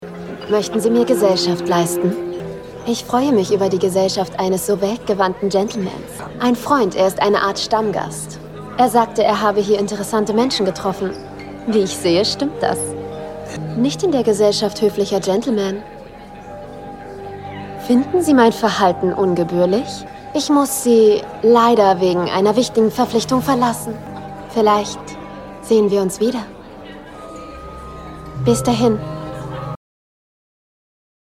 sehr variabel
Jung (18-30)
Sächsisch
Synchron sinnlich, spielerisch
Lip-Sync (Synchron)